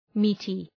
Shkrimi fonetik {‘mi:tı}
meaty.mp3